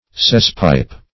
Search Result for " cesspipe" : The Collaborative International Dictionary of English v.0.48: Cesspipe \Cess"pipe`\ (s[e^]s"p[imac]p`), n. A pipe for carrying off waste water, etc., from a sink or cesspool.